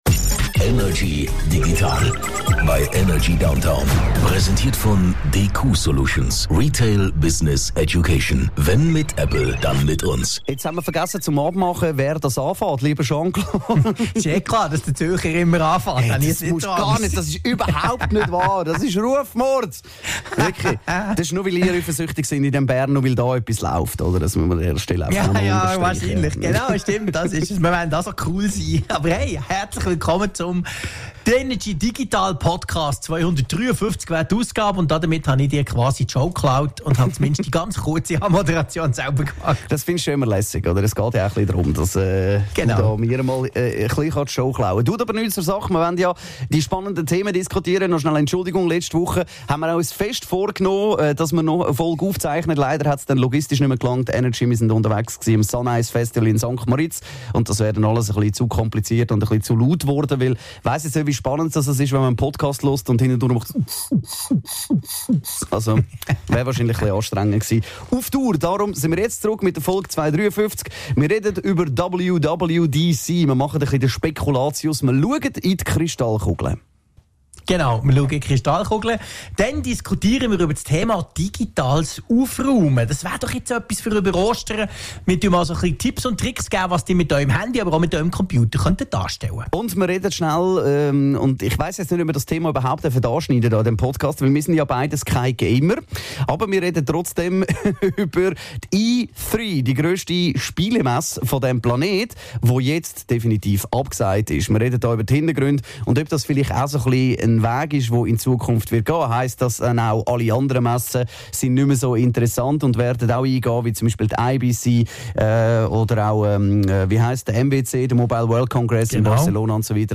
im Energy Studio
aus dem HomeOffice über die digitalen Themen der Woche.